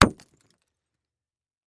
Звуки камня
Шорох камней, ударяющихся друг о друга